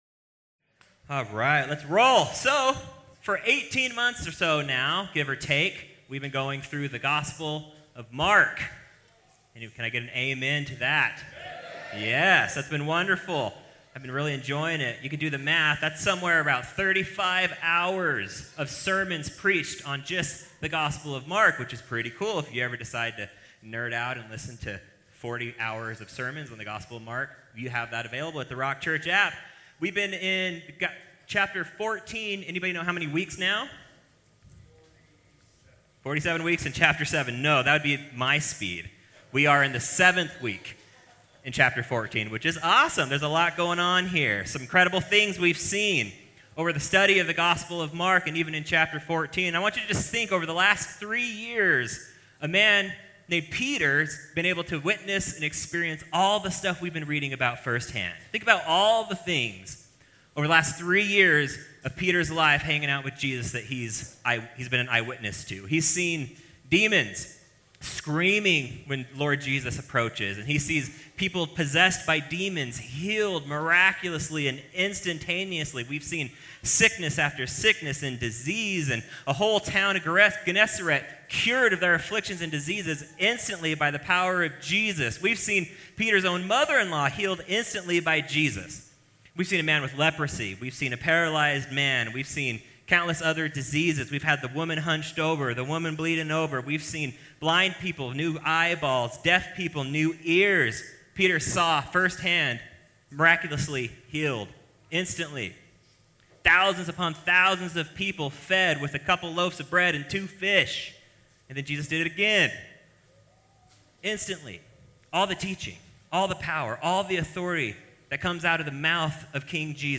In this sermon, we'll look at five things that ultimately contributed to Peter’s failure.